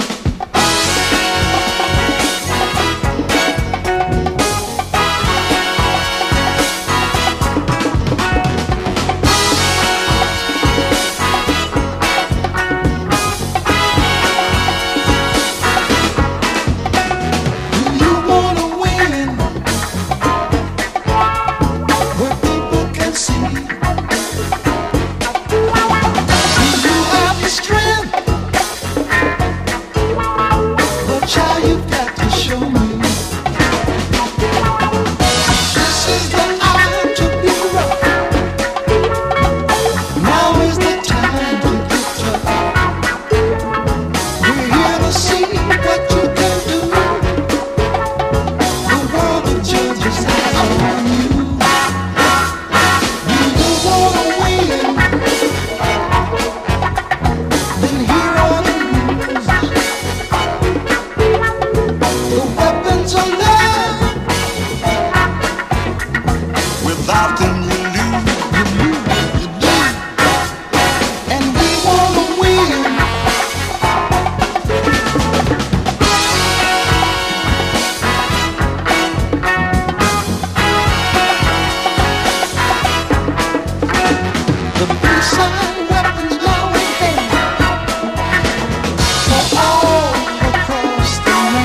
パーカッションが弾けるファンキー・シカゴ・ソウル！